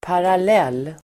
Uttal: [paral'el:]